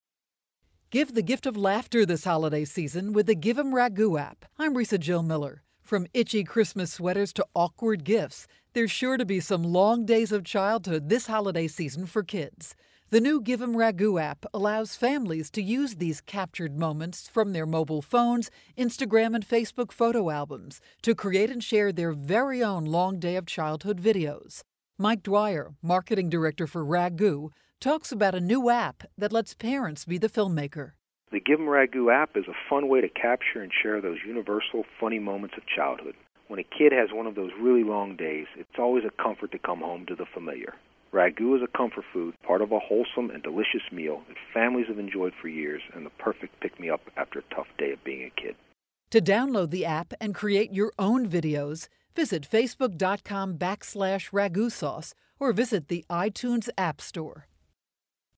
December 11, 2012Posted in: Audio News Release